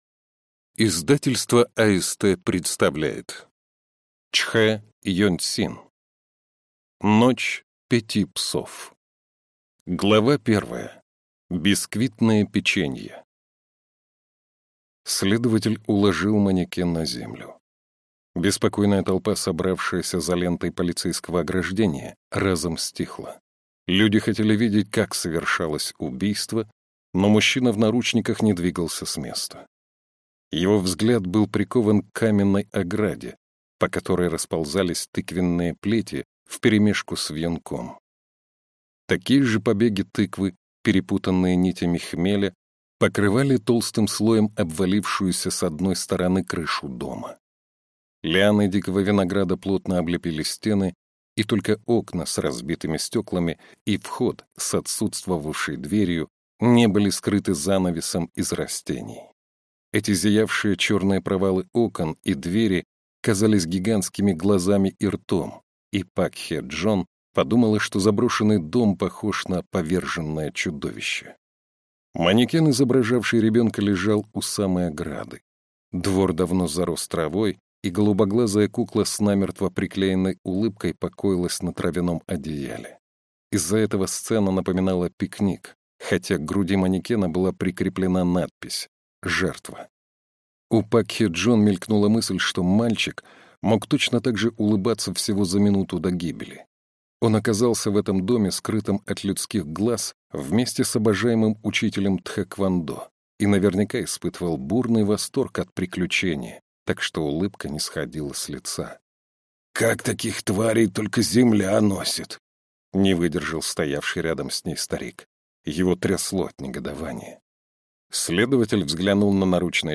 Аудиокнига Ночь пяти псов | Библиотека аудиокниг